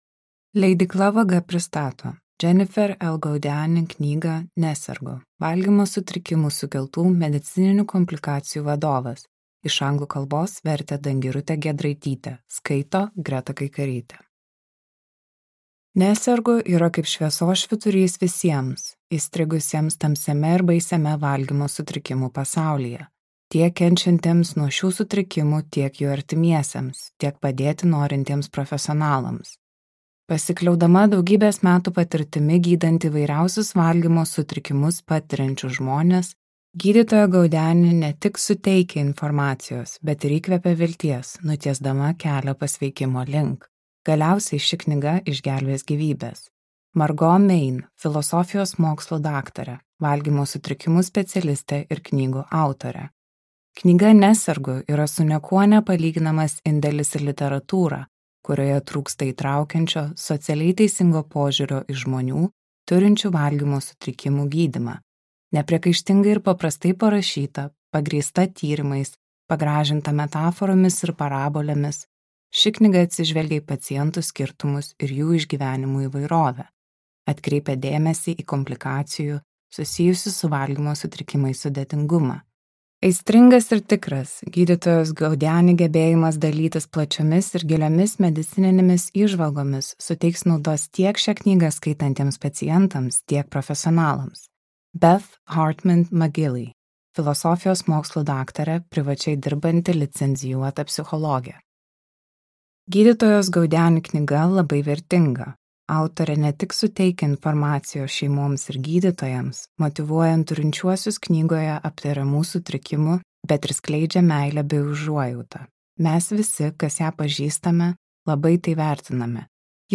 Gydytojos Jennifer L. Gaudiani audioknyga